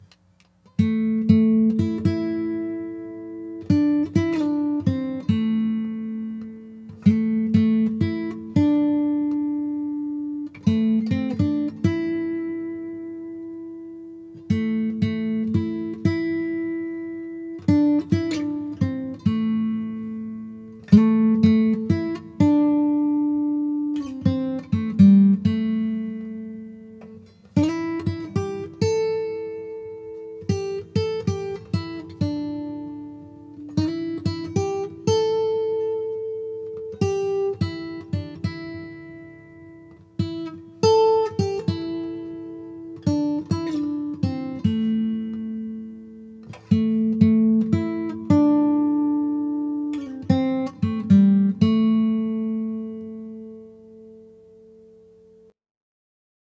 It is one of the pieces I play pretty much every time I pick up my guitar.